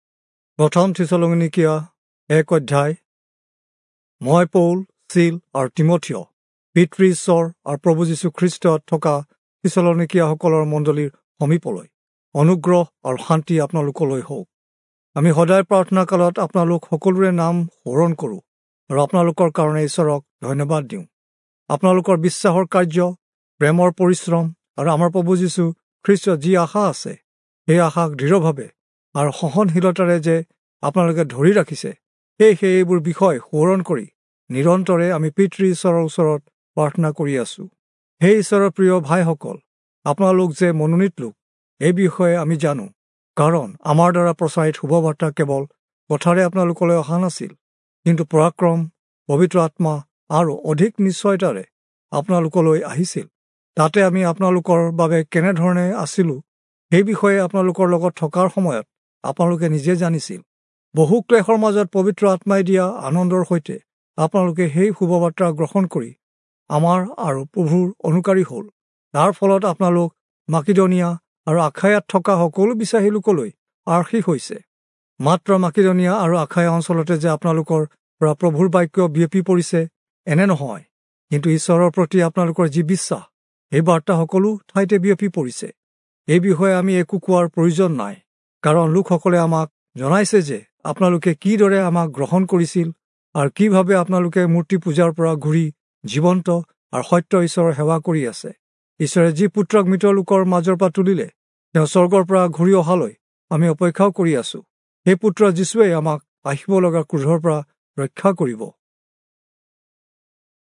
Assamese Audio Bible - 1-Thessalonians 2 in Urv bible version